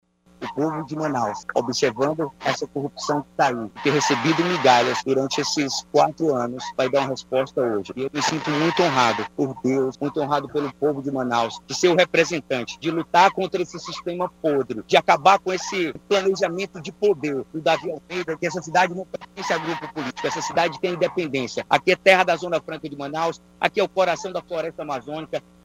Antes de votar conversou com a imprensa e relatou que Manaus precisa dar uma resposta na urna contra ao que o candidato chama de sistema de poder.